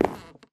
MinecraftConsoles / Minecraft.Client / Windows64Media / Sound / Minecraft / step / wood6.ogg
wood6.ogg